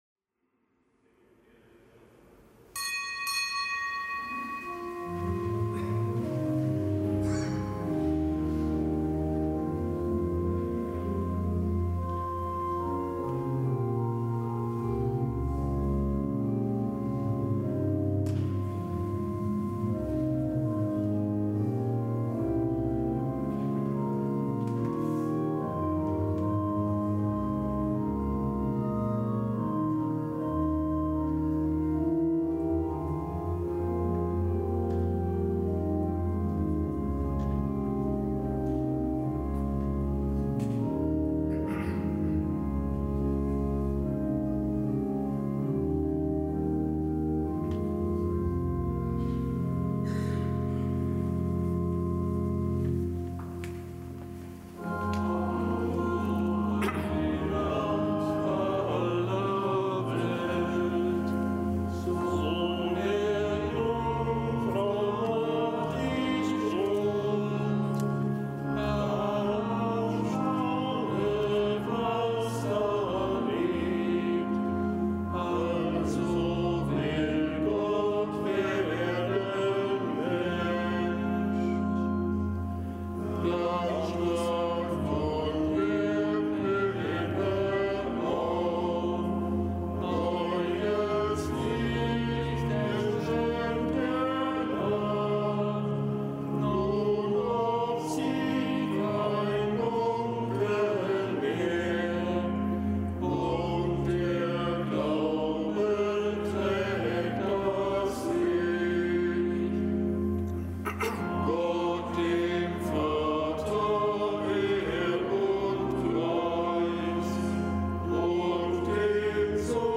Kapitelsmesse aus dem Kölner Dom am Mittwoch der vierten Adventswoche.